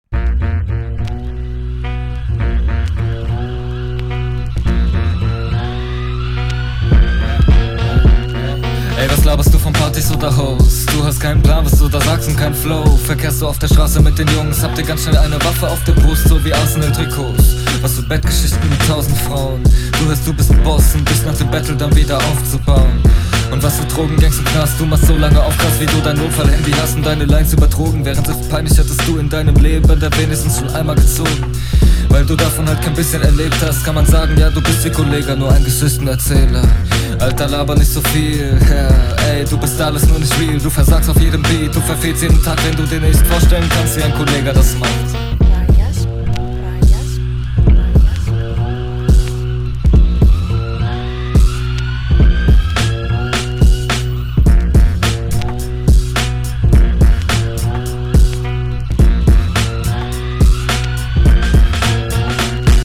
Cooler Beat! Stimmlich kommt das schön arrogant, aber trotzdem druckvoll genug!